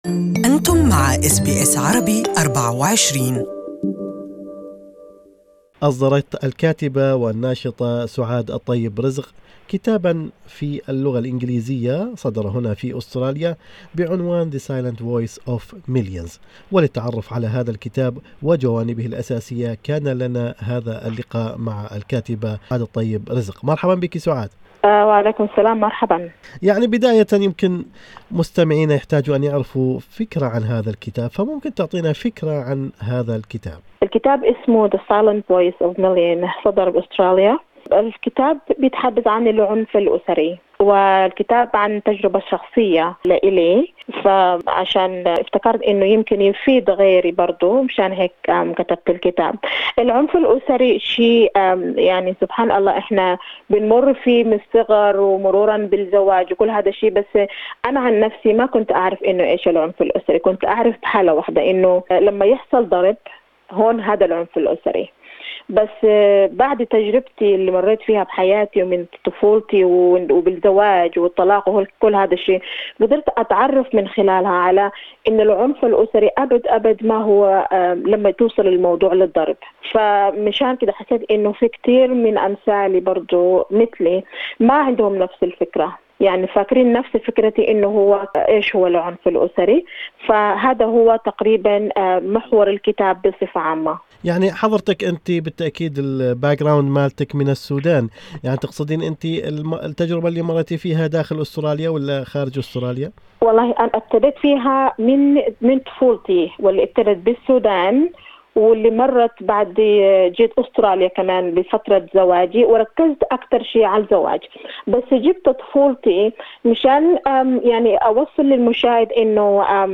In an interview with SBS Arabic24